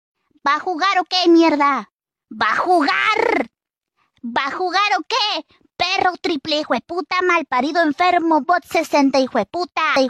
va a jugar o que anime Meme Sound Effect